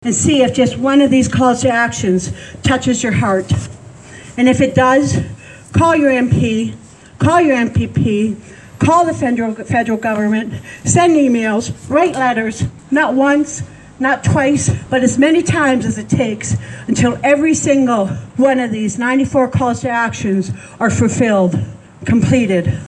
The one-hour event was a commemoration of Orange Shirt Day and National Day for Truth and Reconciliation.